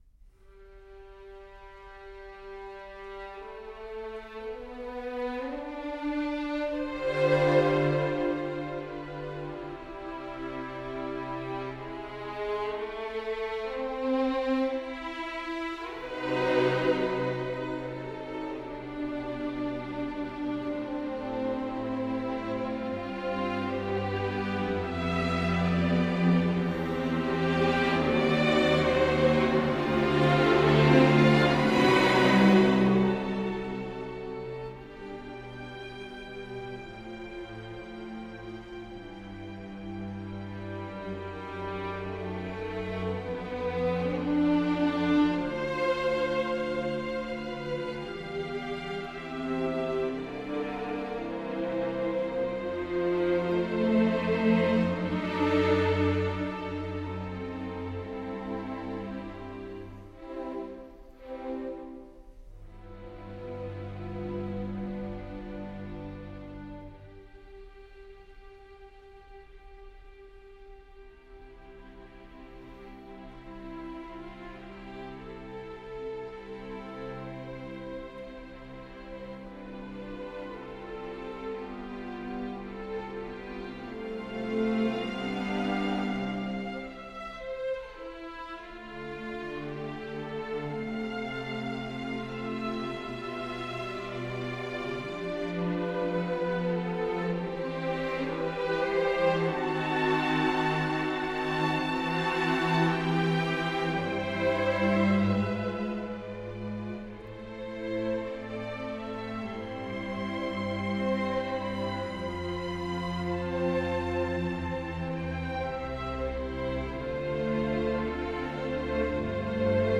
2. Larghetto